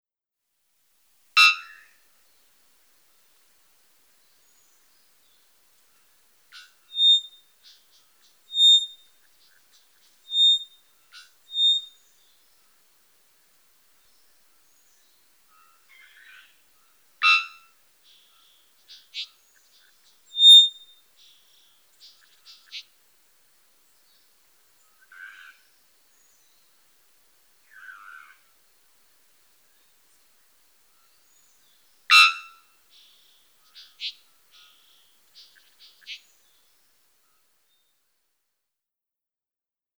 THREE-WATTLED BELLBIRD OR-352
Monteverde, Costa Rica
July, mid-morning
A few sounds from the tropics, illustrating the variety of songs in the repertoire of a three-wattled bellbird singing the learned dialect of northern Costa Rica.